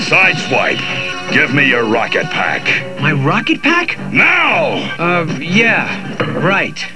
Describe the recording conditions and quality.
"NOW!!!!" - with echo for that added anger effect! From "More Than Meets The Eye"